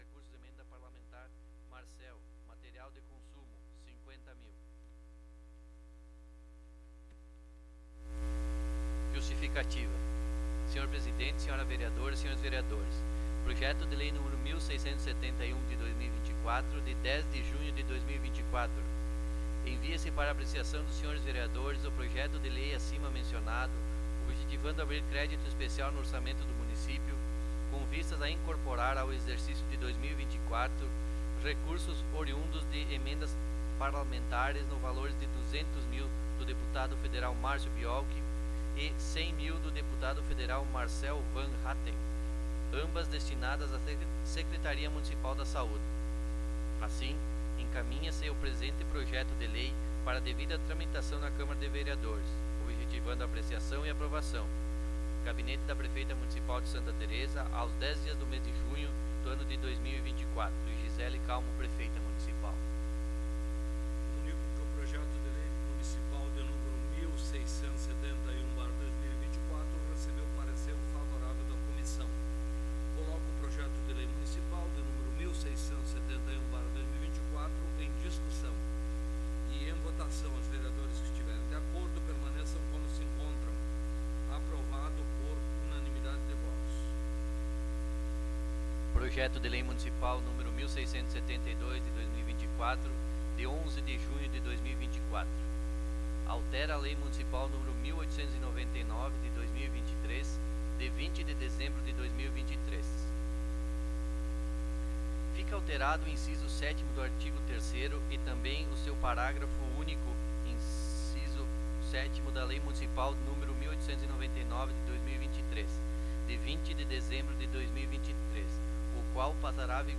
09° Sessão Ordinária de 2024
Áudio da Sessão